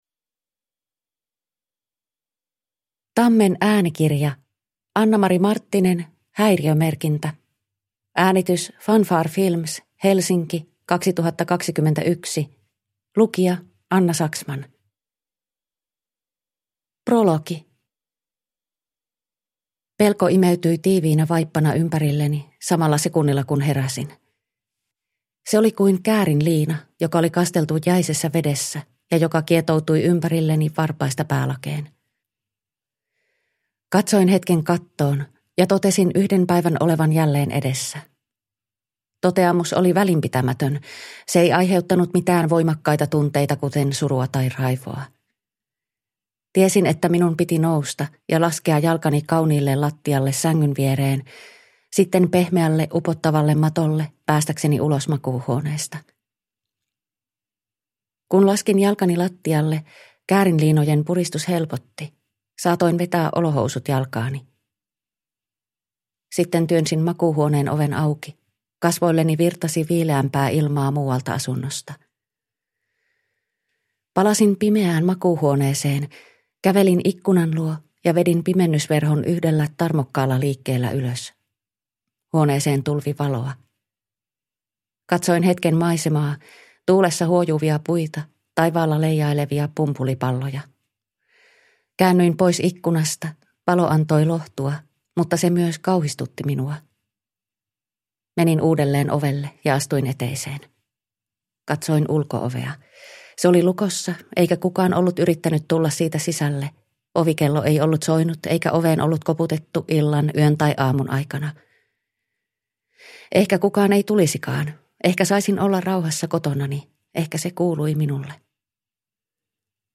Häiriömerkintä (ljudbok) av Annamari Marttinen